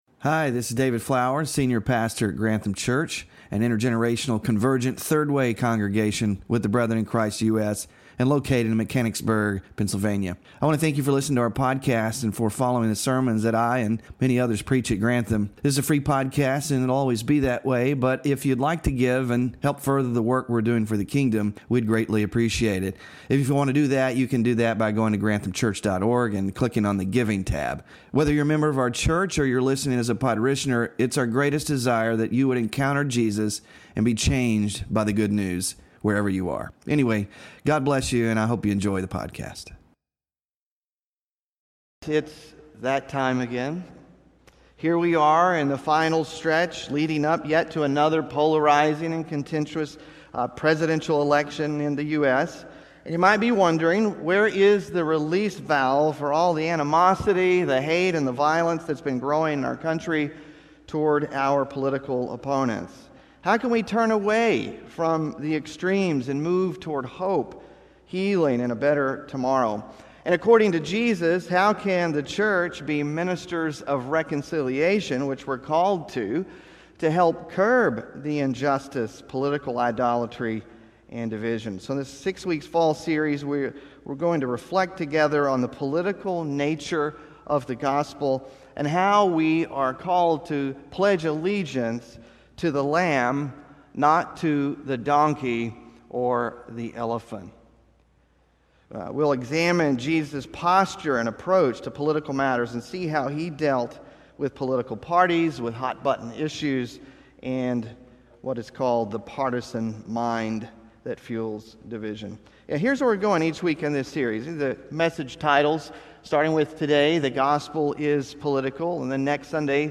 THE GOSPEL IS POLITICAL SERMON SLIDES (1st OF 6 IN SERIES) SMALL GROUP DISCUSSION QUESTIONS (10-6-24) BULLETIN (10-6-24)